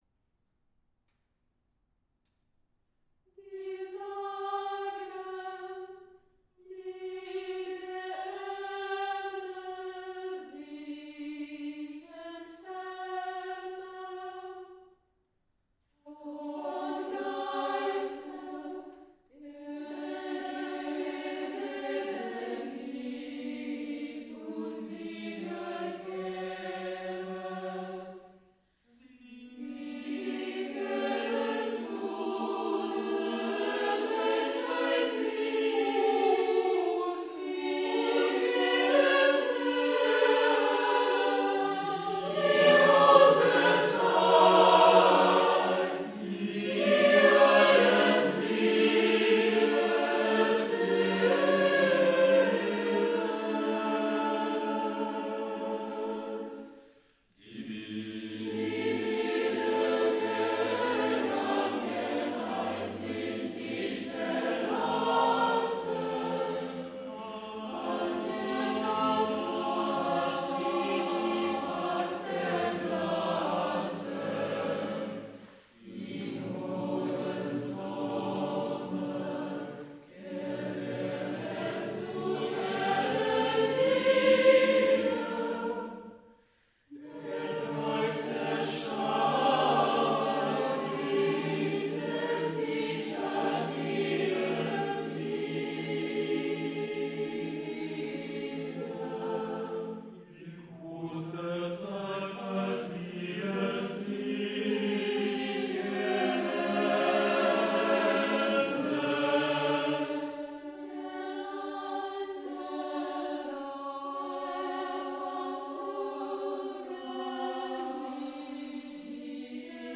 lied pour chœur a cappella sur un poème extrait des Poèmes à la fenêtres (1832-43)  (juil.-déc. 2019)
(je découvre en juin 24 les symphonies de Franz Schmidt, dont le 2d mouvement de la 3e se dénoue (>6'45) harmoniquement comme notre a cappella en ♯do mineur)
montage (format mp3) isssu d'une séance de travail effectuée le jeudi 6 avril 23.